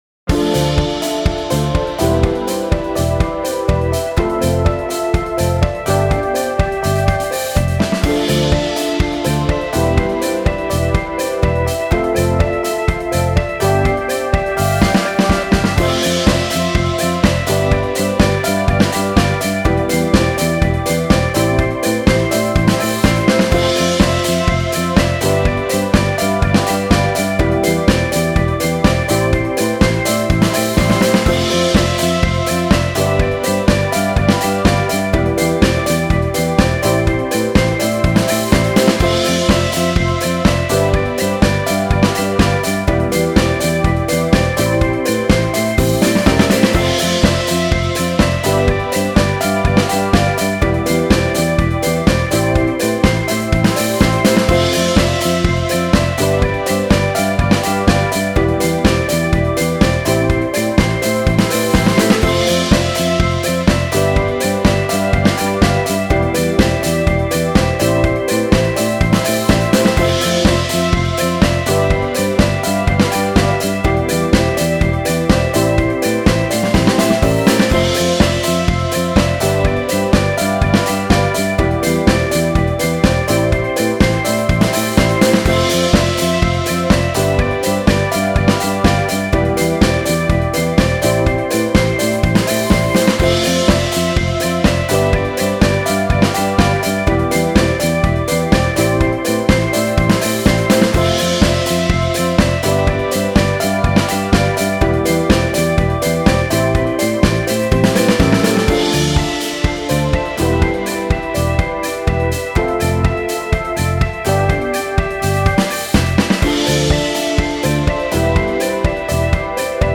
メロウ・切ない